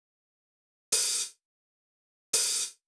Open Hat.wav